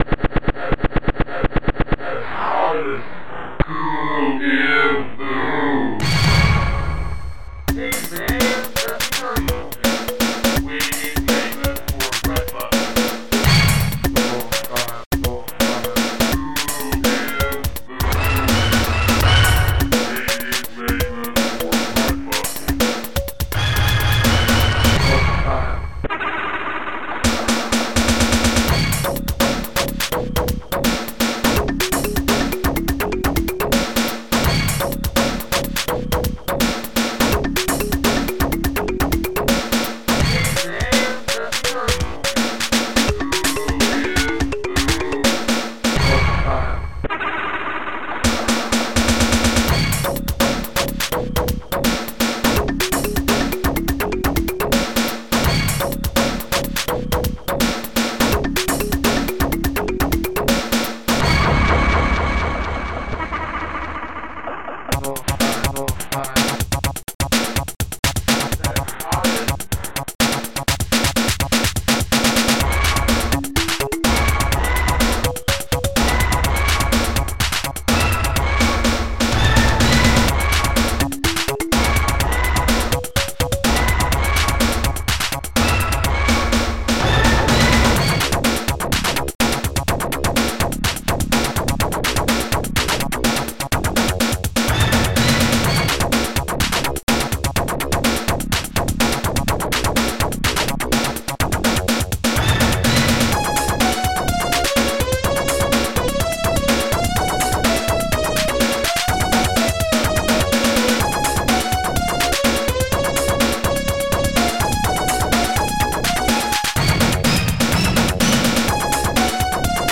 st-01:M1-BASSD
st-01:M1-SNARE
st-01:M1-HANDCLAP
st-02:laugh
st-01:heavybass
st-01:M1-KALIMBA